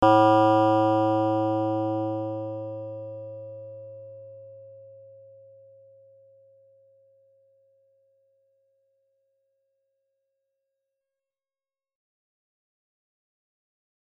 Synthesis of organic sounds for electroacoustic music : cellular models and the TAO computer music program.